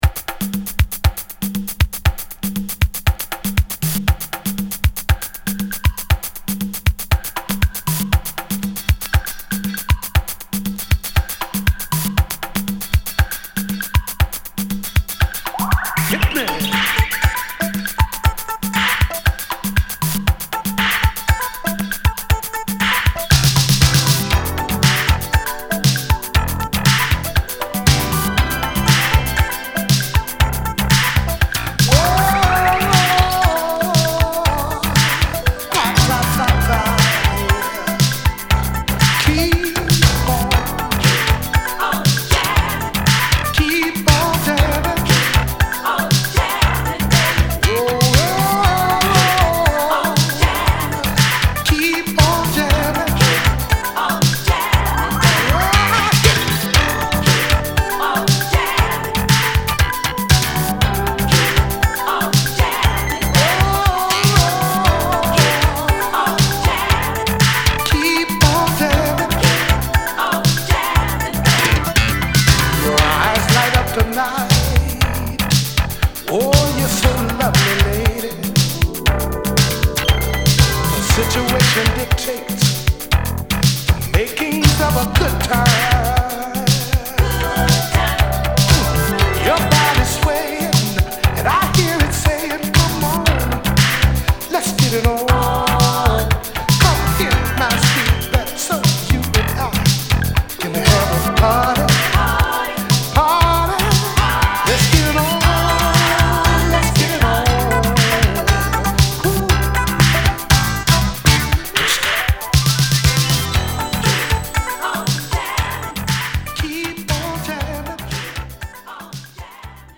・ DISCO 70's 12'
Mellow Modern Boogie